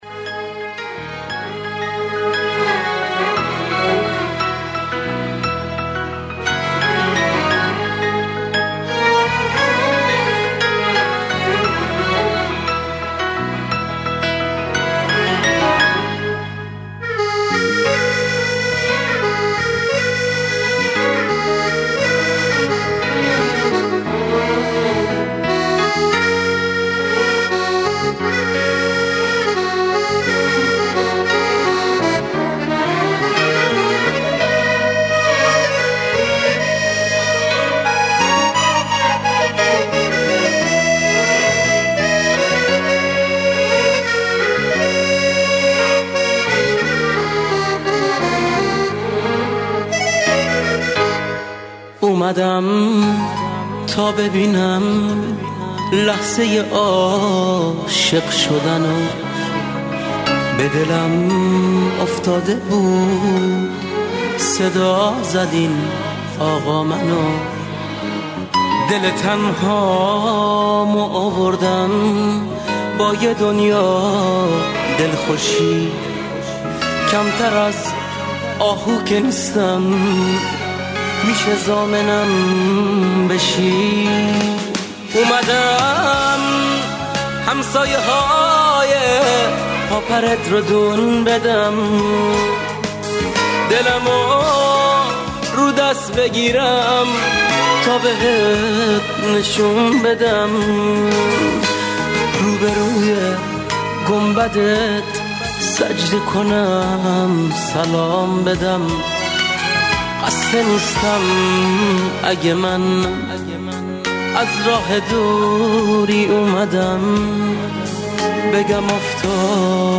این ترانه‌ی دل‌انگیز